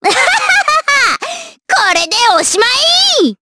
Miruru-Vox_Skill4_jp.wav